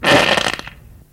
文件夹里的屁 " 屁09
描述：从freesound上下载CC0，切片，重采样到44khZ，16位，单声道，文件中没有大块信息。
Tag: 喜剧 放屁 效果 SFX soundfx 声音